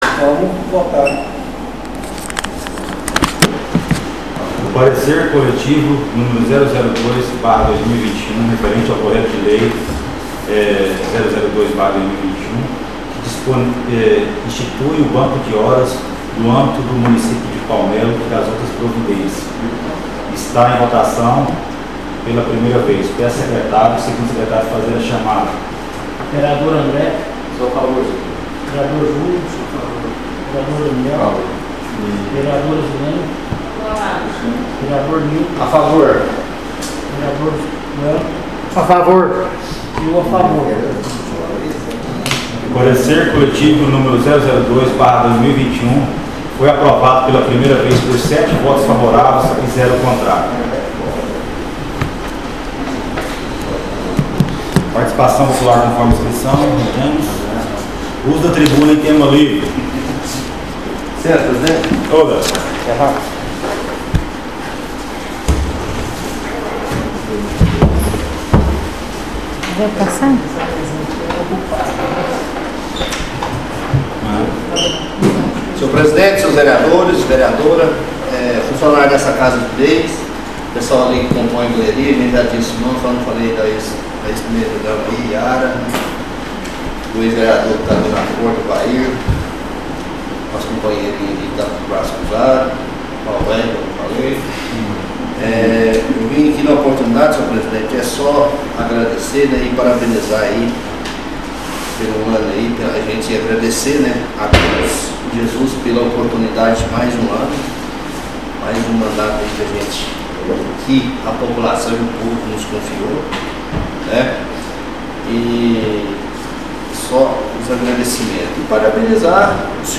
SESSÃO ORDINARIA DIA 17/02/2021 PARTE 3